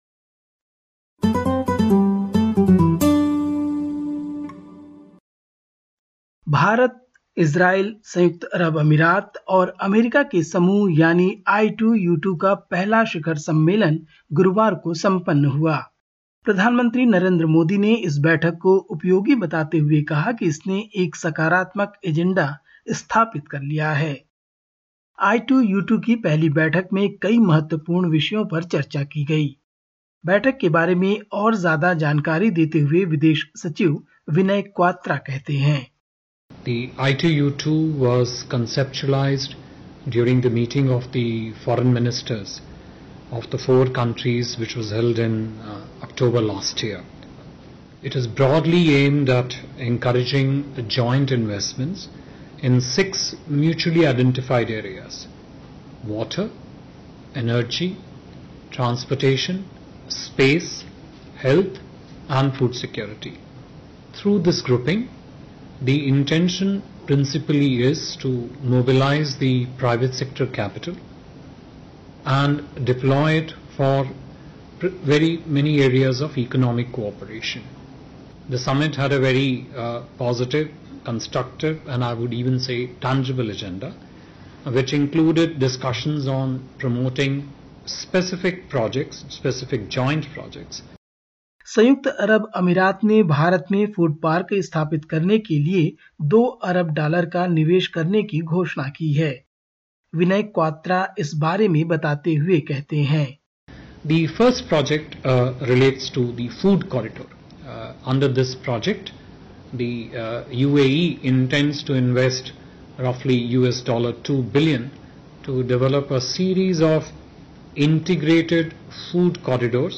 Listen to the latest SBS Hindi report from India. 15/07/2022